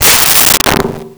Metal Strike 01
Metal Strike 01.wav